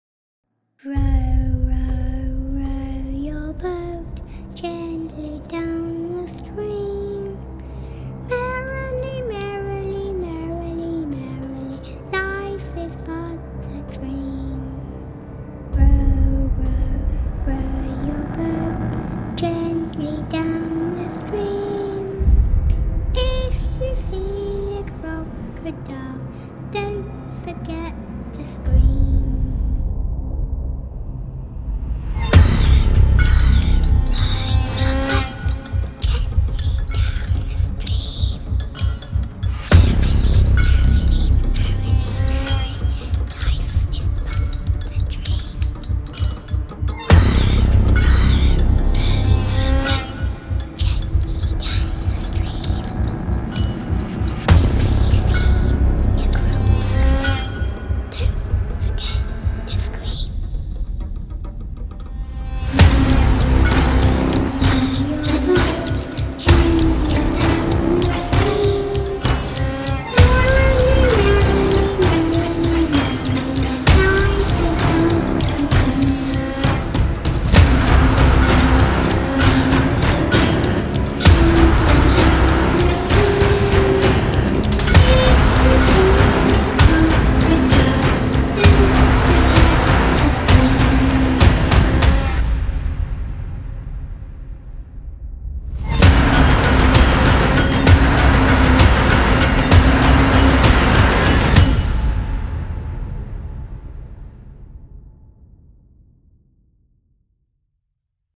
Creepy Nursery Rhymes